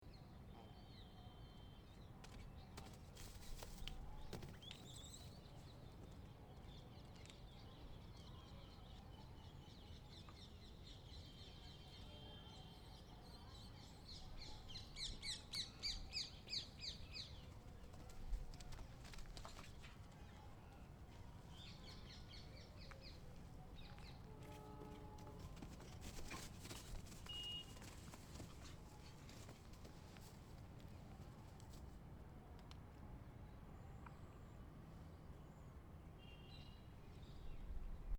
26/11/2013 14:00 Dans un des palais en ruine, des singes courent partout autour de nous.
singes.mp3